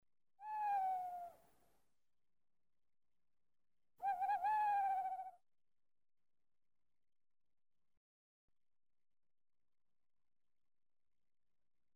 owl.mp3